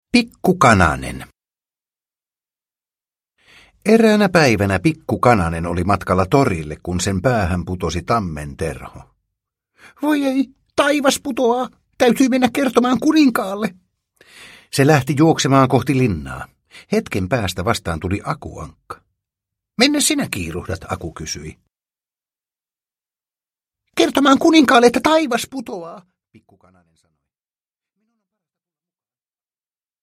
Pikku Kananen – Ljudbok – Laddas ner